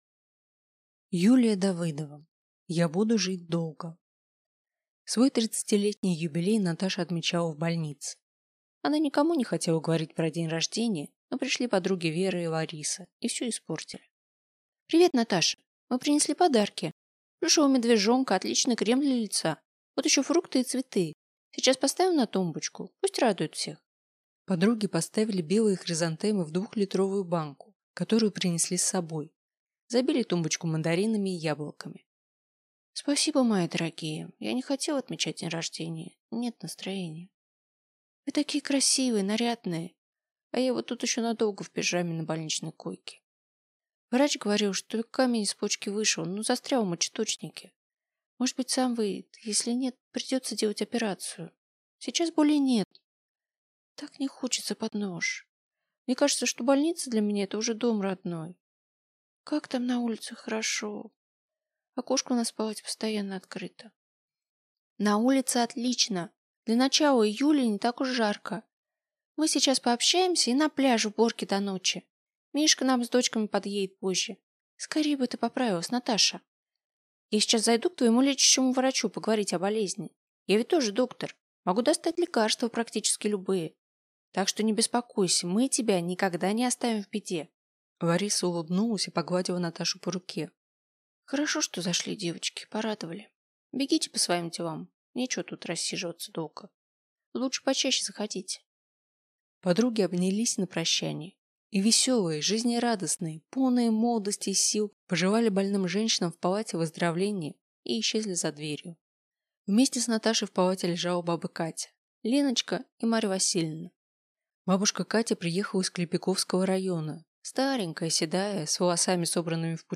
Аудиокнига Я буду жить долго